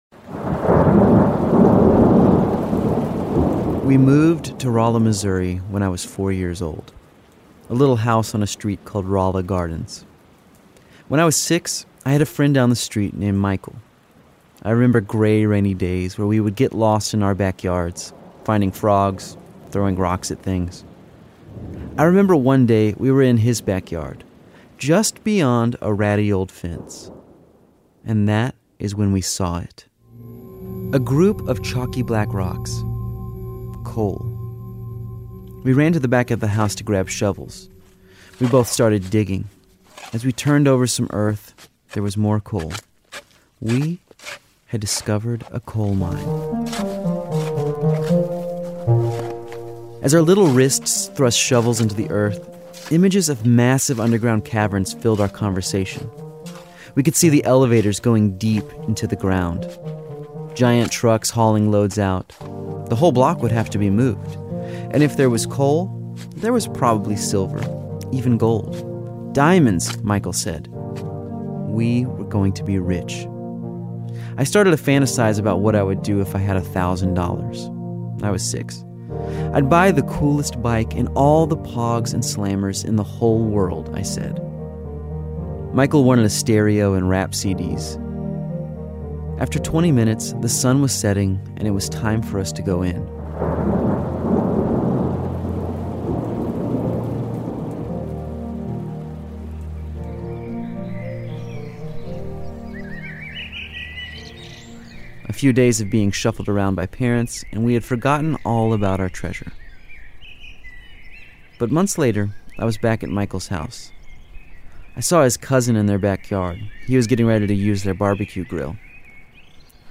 Prepare to be embedded into a gaggle of neighborhood kids, playing at the peak of summer.